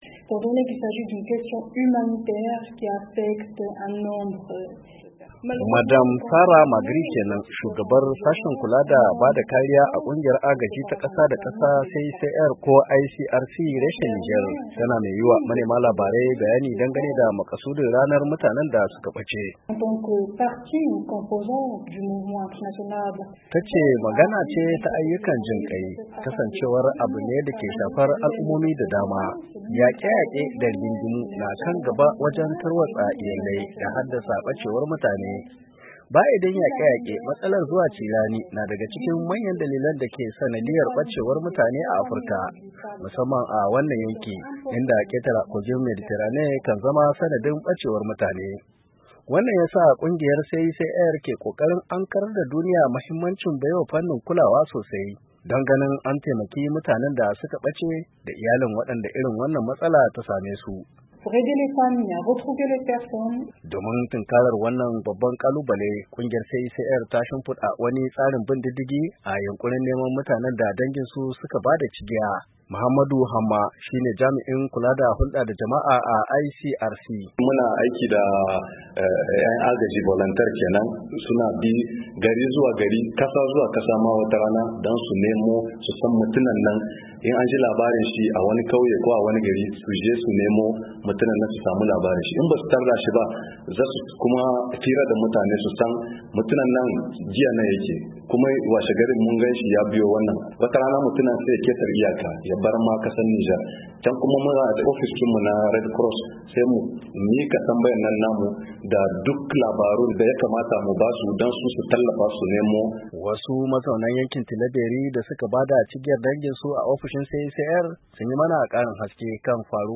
Wasu mazaunan yankin Tilabery da suka bada cigiyar danginsu a ofishin CICR sun yi mana karin haske kan faruwar wannan al’amari da hanyoyin da aka bi.